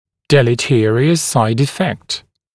[ˌdelɪ’tɪərɪəs saɪd-ɪ’fekt][ˌдэли’тиэриэс сайд-и’фэкт]вредный побочный эффект, пагубный побочный эффект